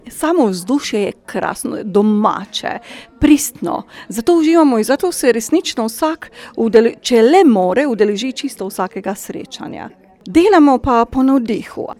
Na literarnem popoldnevu z ljubitelji pisane besede Meškov svinčnik